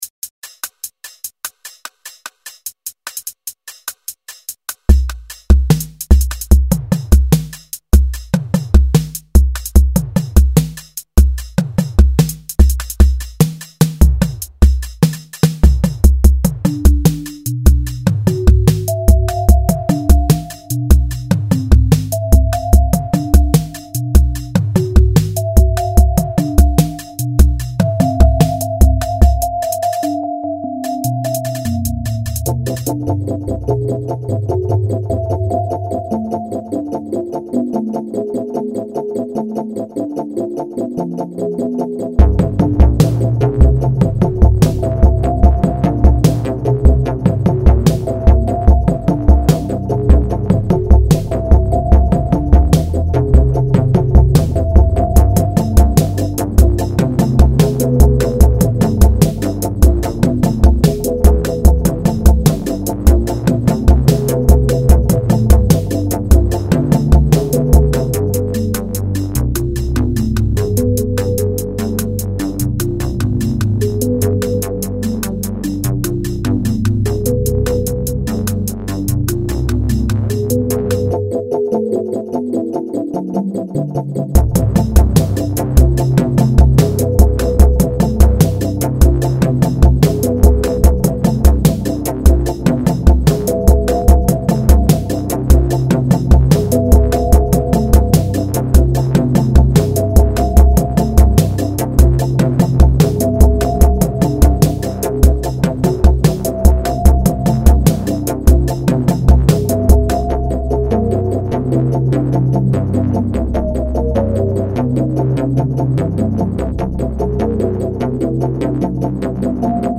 rhythmic